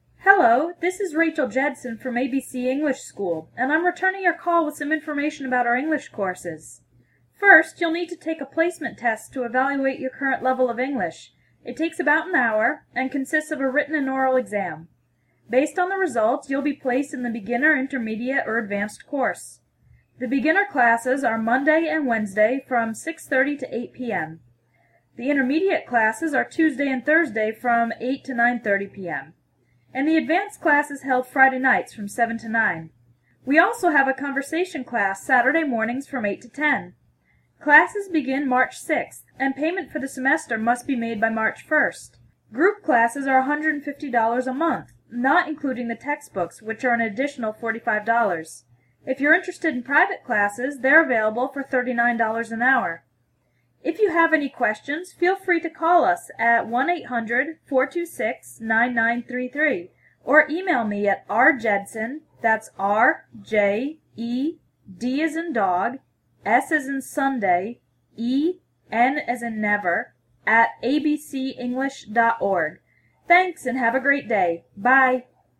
Listen to this telephone message and take the quiz below to test your understanding. There are two listening options: fast (for intermediate to advanced students) and slow (for beginner to pre-intermediate students).
Fast
english-info-fast.mp3